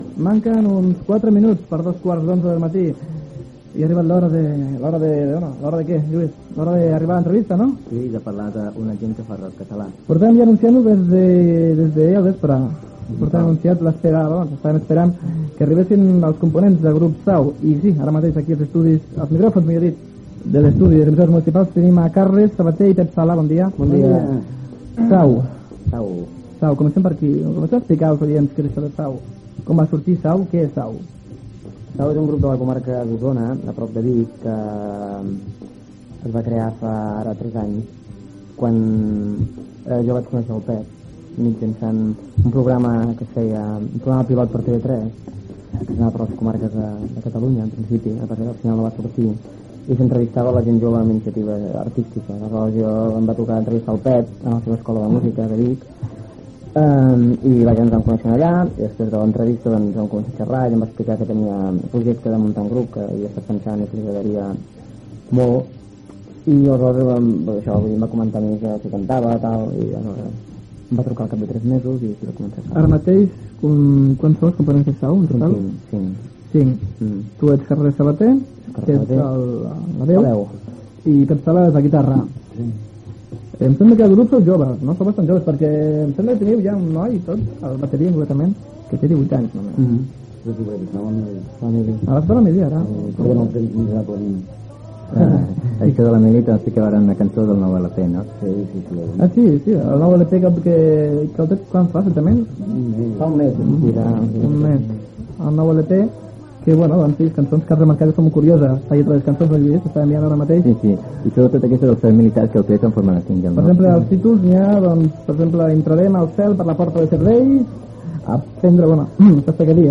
826a16aa683d7306fbe3a1823b459f0f9c26b947.mp3 Títol Ràdio Fòrum Emissora Ràdio Fòrum Titularitat Pública municipal Descripció Hora i entrevista a Carles Sabater i Pep Sala del grup Sau que promocionen el disc "Per la porta de servei".